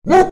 外星人狐狸叫
描述：Elictronically改变狐狸树皮 分层和模块化 SofT听到质量
标签： 未来主义 动物 声音效果器 外星人 外星人 狐狸 狐狸
声道立体声